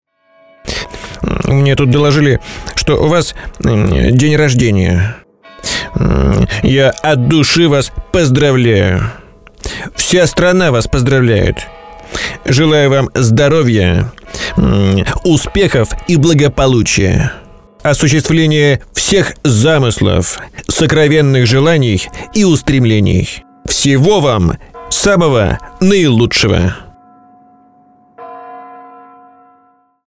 Вся страна радостно поздравляет вас с Днем рождения